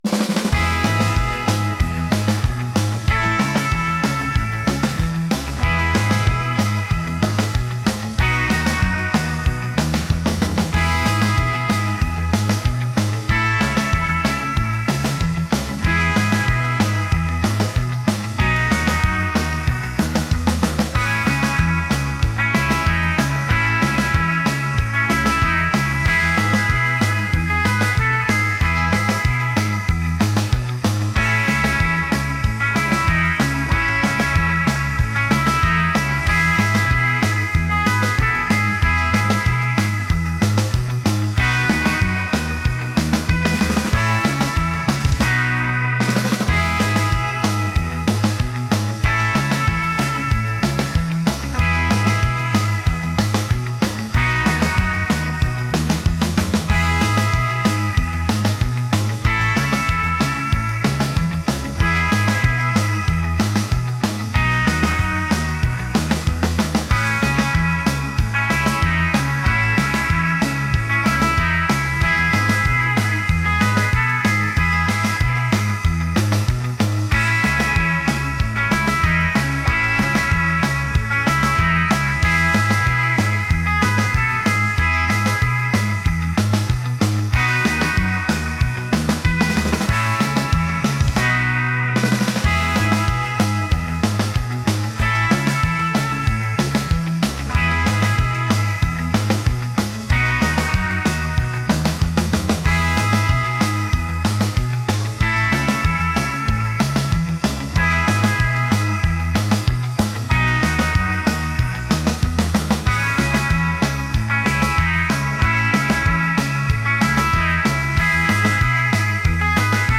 retro | rock | energetic